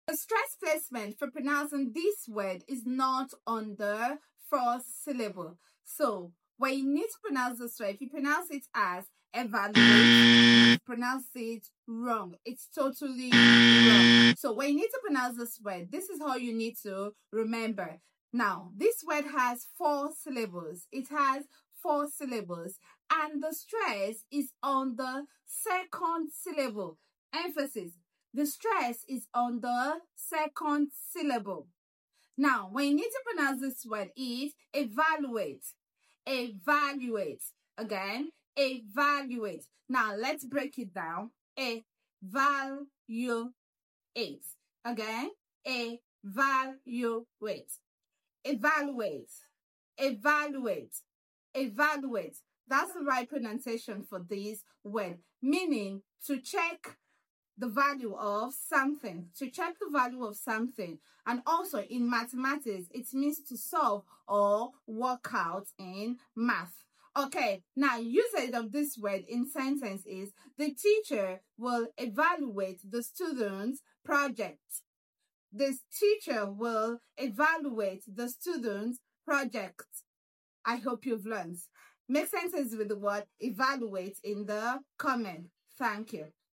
Pronunciation: ih – VAL – you – ate (4 syllables) 📖 Meaning: to judge, check, or find the value of something.
👉 Clap it out with me: E 👏 – val 👏 – u 👏 – ate 👏